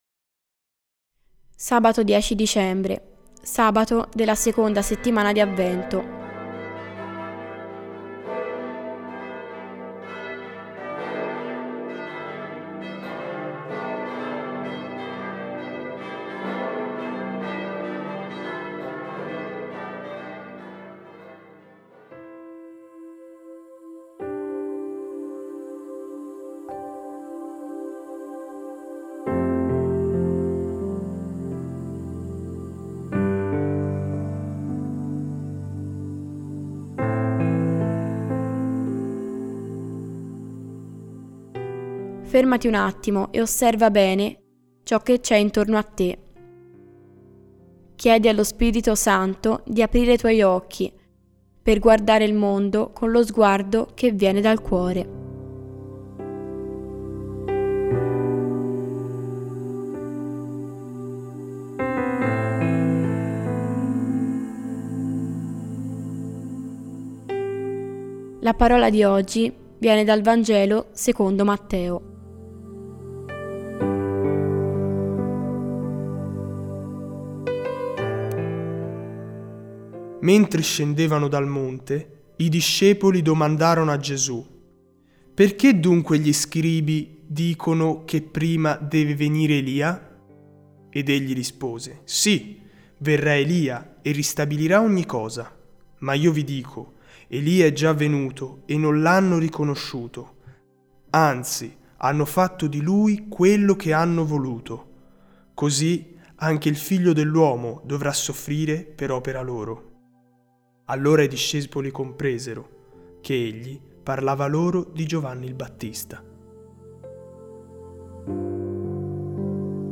Instrumental Prayer Worship Music